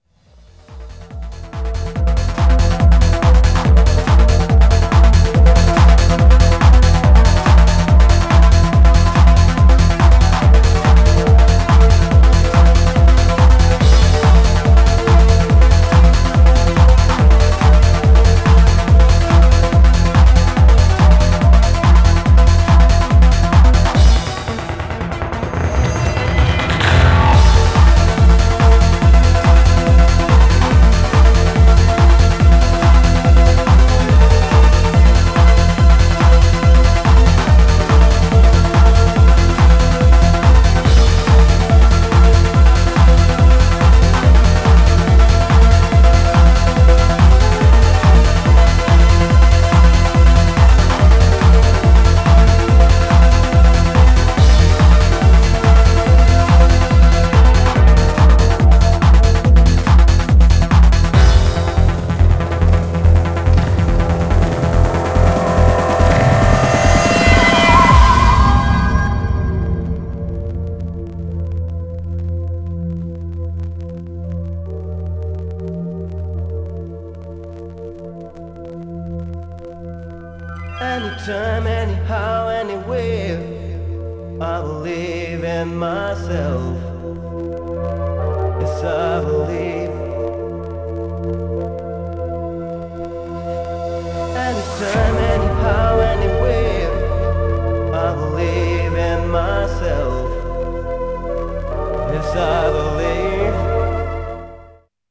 Styl: Progressive, House, Trance